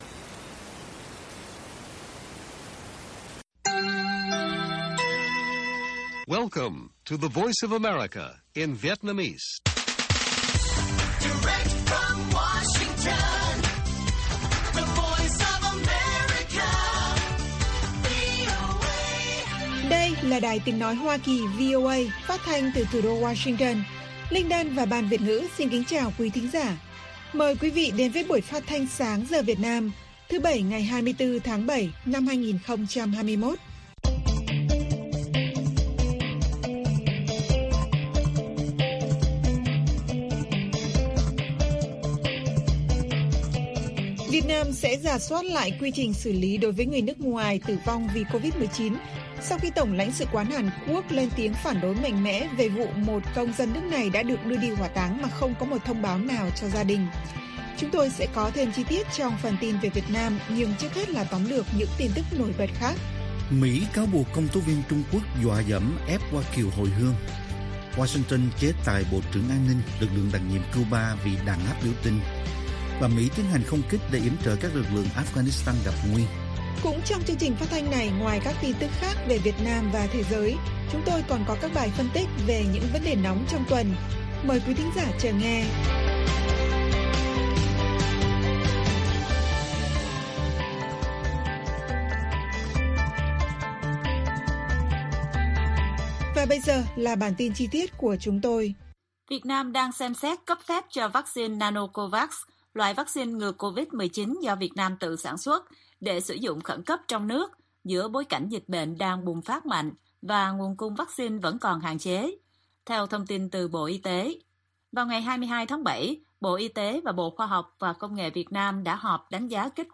Bản tin VOA ngày 24/7/2021